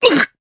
Riheet-Vox_Damage_jp_03.wav